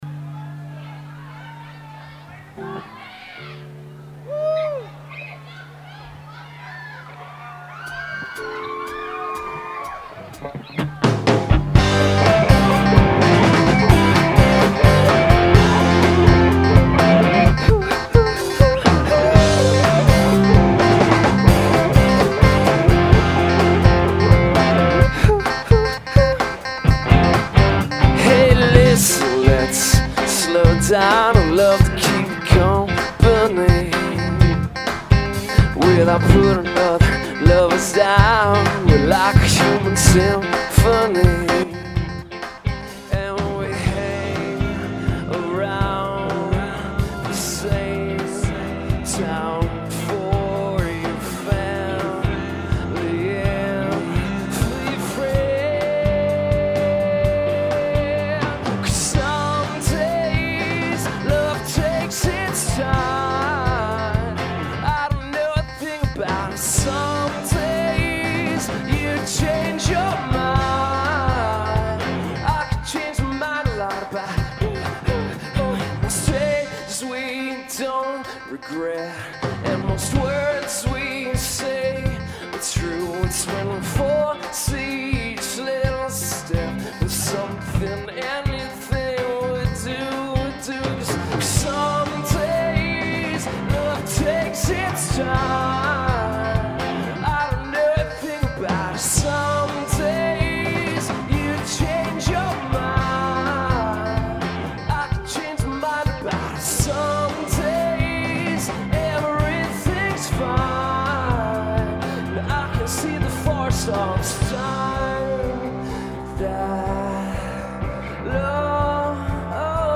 quinnipiac college april 25 2003